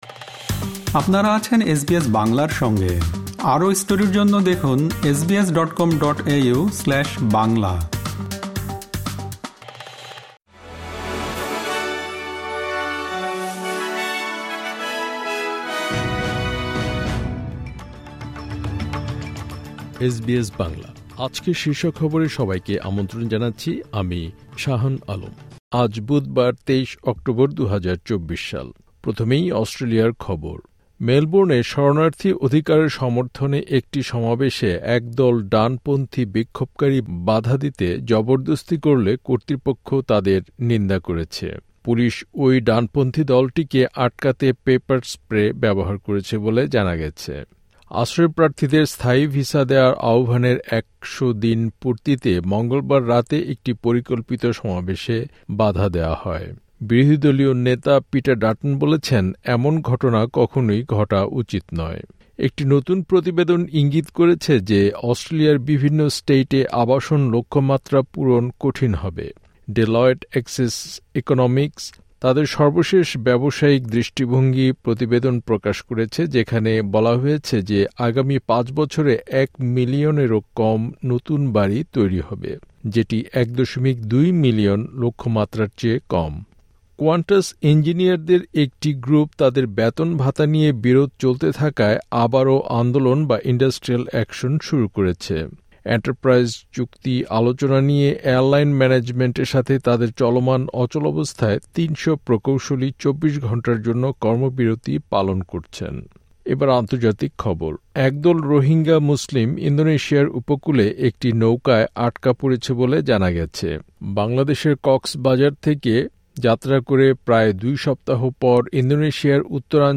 এসবিএস বাংলা শীর্ষ খবর: ২৩ অক্টোবর, ২০২৪